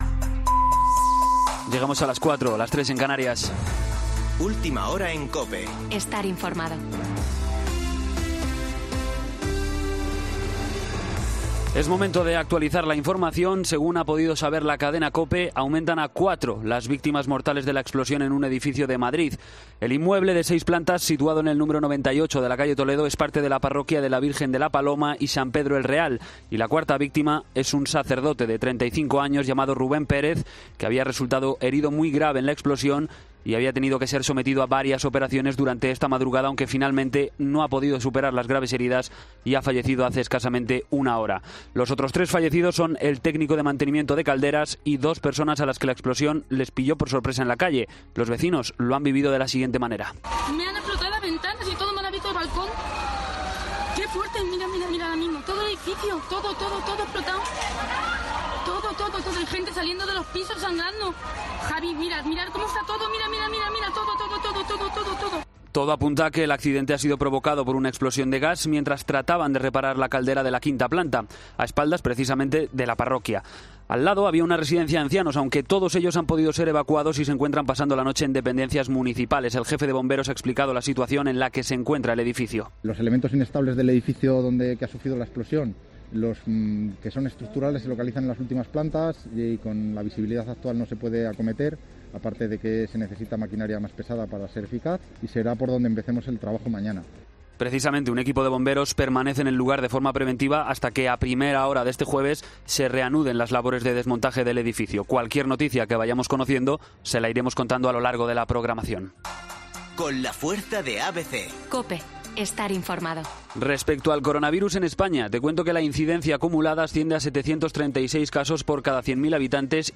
Boletín de noticias COPE del 21 de enero de 2021 a las 04.00 horas